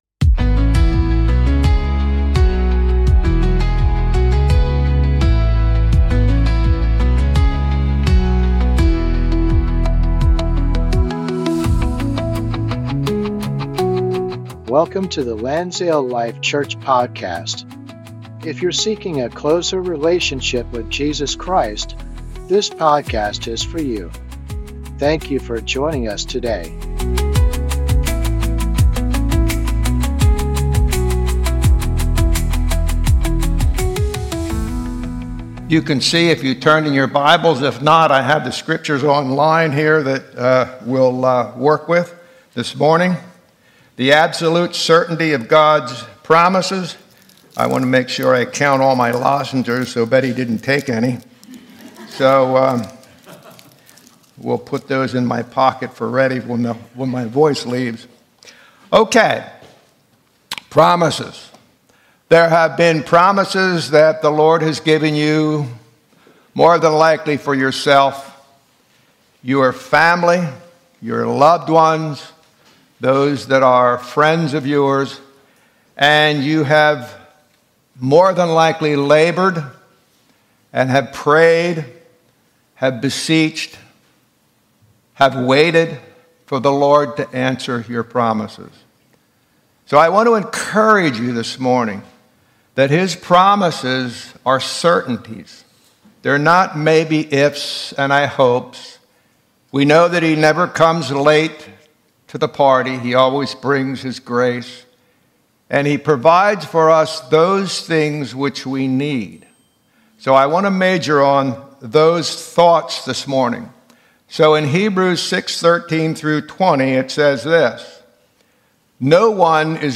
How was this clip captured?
Sunday Service - 2025-08-31